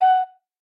flute.ogg